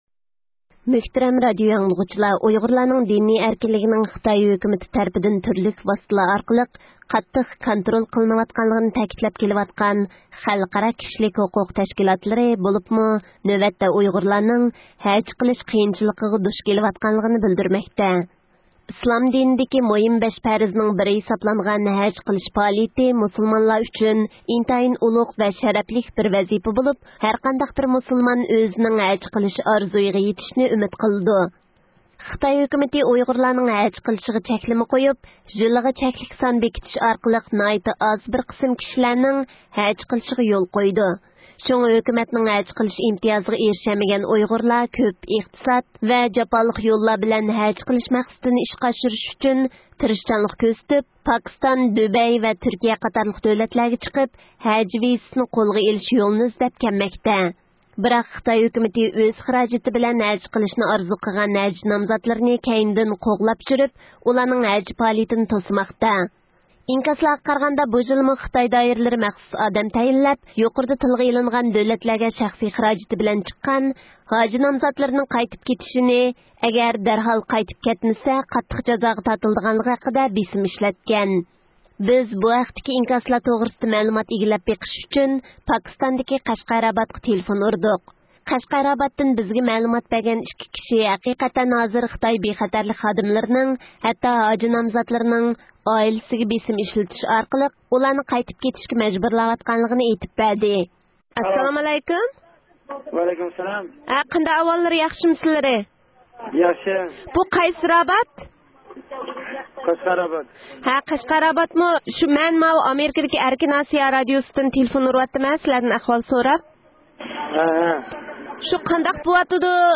بىز بۇ ھەقتىكى ئىنكاسلار توغرىسىدا مەلۇمات ئىگىلەپ بېقىش ئۈچۈن، پاكىستاندىكى قەشقەر راباتقا تېلېفۇن ئۇردۇق.
ئۇيغۇرلارنىڭ مىللى رەھبىرى، كىشىلىك ھوقۇق پائالىيەتچىسى رابىيە قادىر خانىم زىيارىتىمىزنى قوبۇل قىلىپ، ئۇيغۇرلارنىڭ ھەج قىلىشقا ئوخشاش دىنىي ئەركىنلىكىدىن مەھرۇم قىلىنىۋاتقانلىقىنى بىلدۈردى.